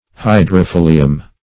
Meaning of hydrophyllium. hydrophyllium synonyms, pronunciation, spelling and more from Free Dictionary.
Search Result for " hydrophyllium" : The Collaborative International Dictionary of English v.0.48: Hydrophyllium \Hy`dro*phyl"li*um\, n.; pl.